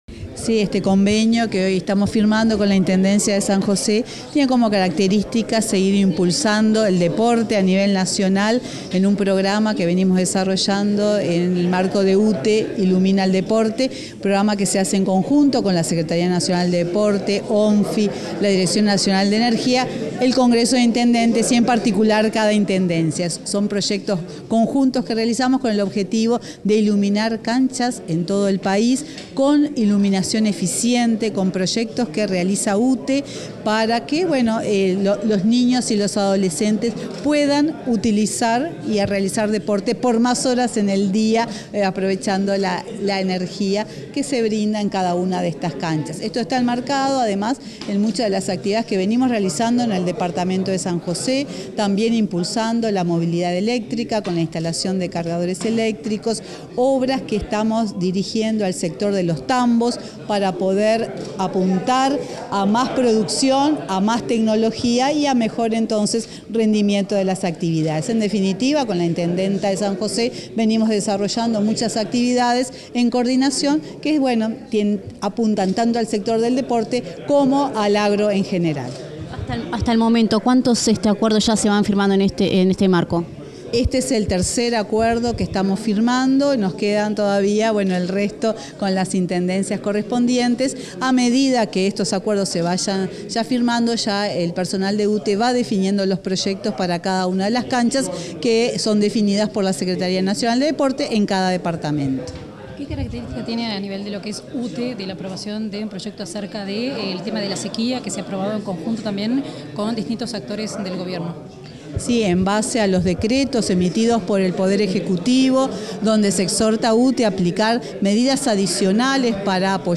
Declaraciones de la presidenta de UTE, Silvia Emaldi
Declaraciones de la presidenta de UTE, Silvia Emaldi 30/03/2023 Compartir Facebook X Copiar enlace WhatsApp LinkedIn Tras la firma de un convenio con la Intendencia de San José, este 30 de marzo, la presidenta de la UTE, Silvia Emaldi, realizó declaraciones a la prensa.